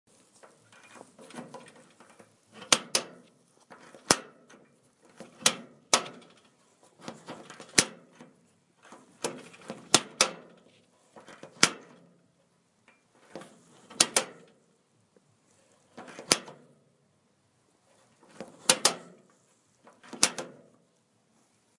Download Button sound effect for free.